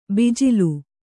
♪ bijilu